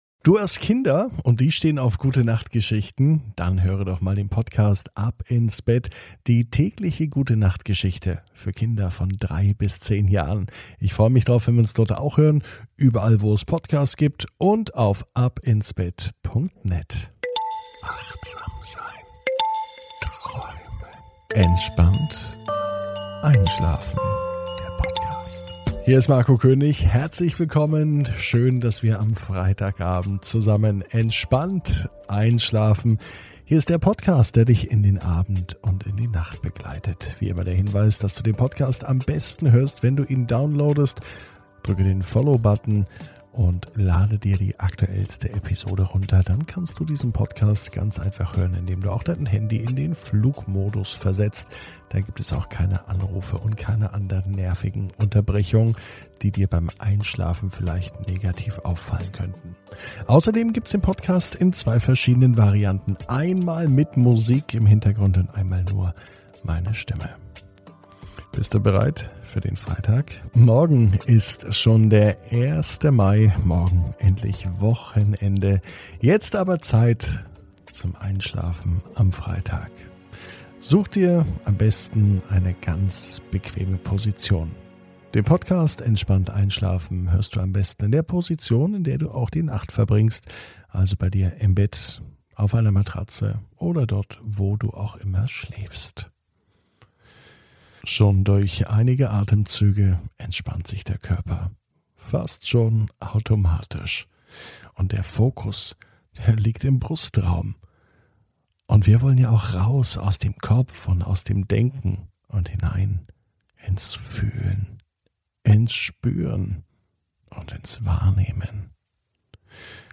(ohne Musik) Entspannt einschlafen am Freitag, 30.04.21 ~ Entspannt einschlafen - Meditation & Achtsamkeit für die Nacht Podcast